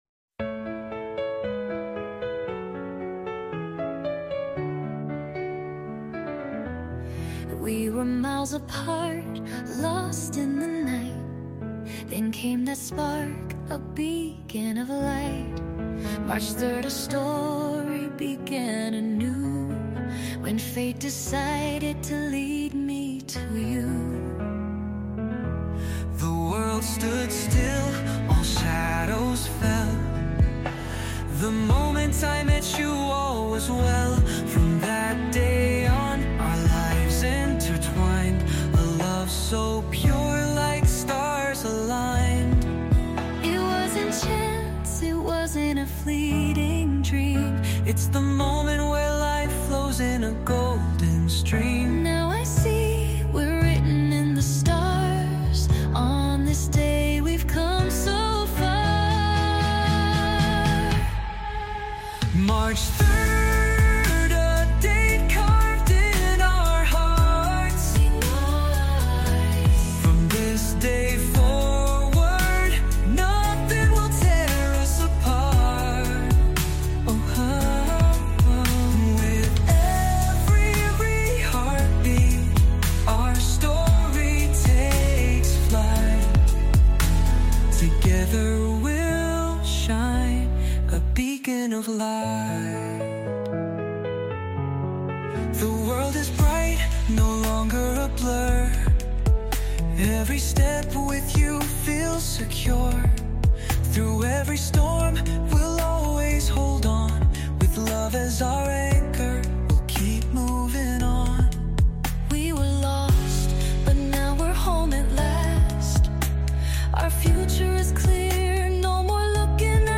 Genre : Pop Ballad - Electronic Pop